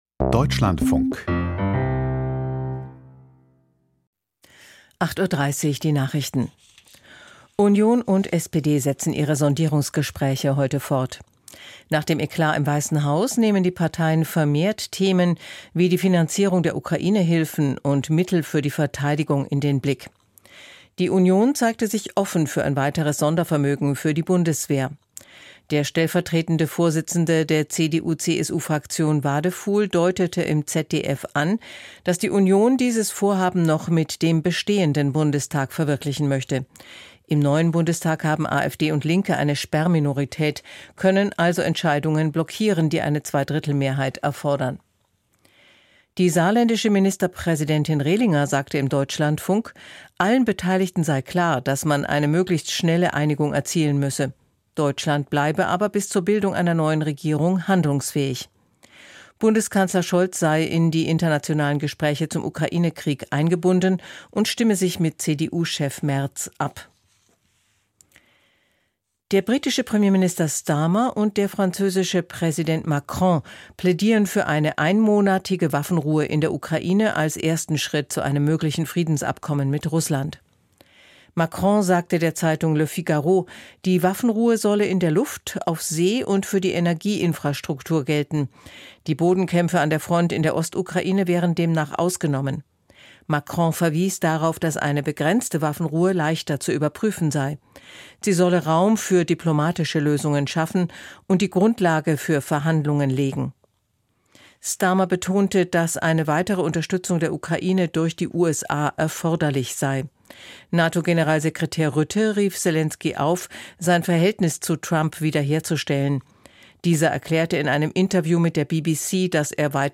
1 Kinder lesen Katzen vor